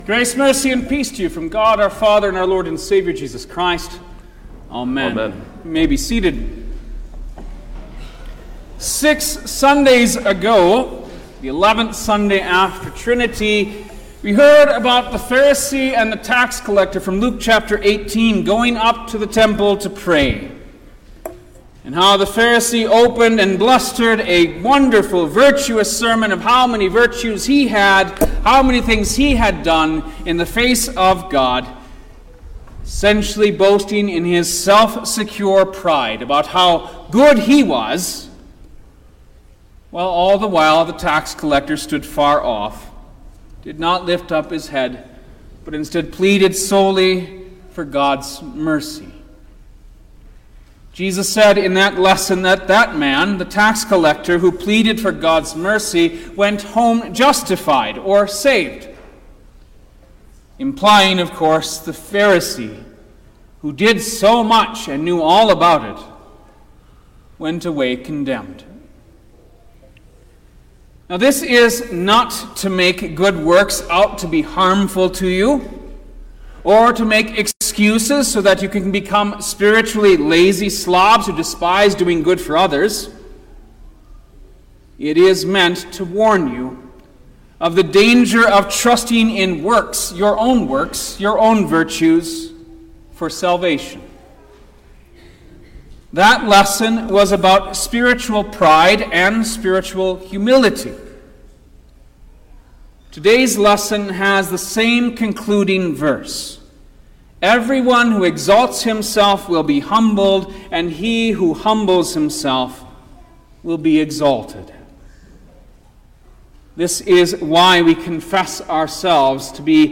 October-1_2023_Seventeenth-Sunday-after-Trinity_Sermon-Stereo.mp3